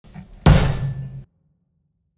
Sound Effects
Table Slam